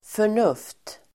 Uttal: [för_n'uf:t]